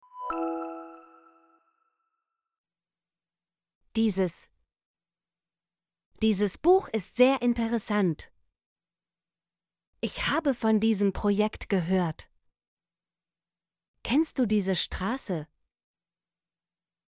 dieses - Example Sentences & Pronunciation, German Frequency List